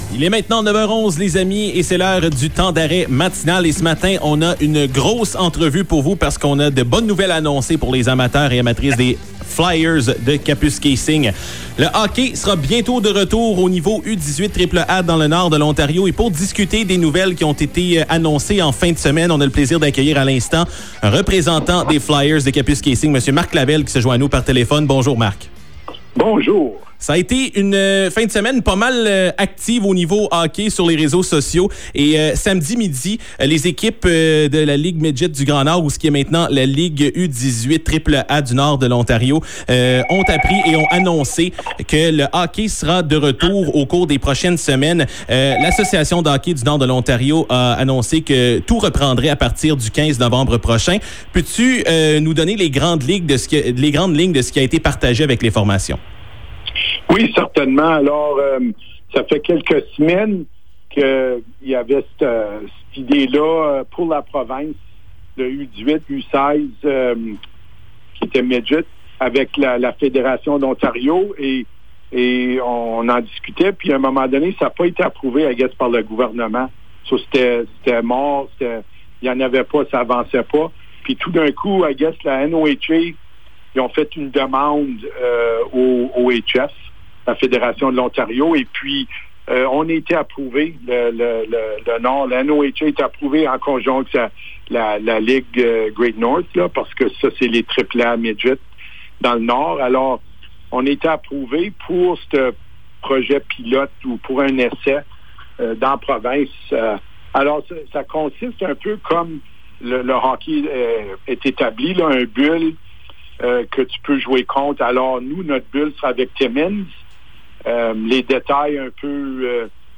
En entrevue à CKGN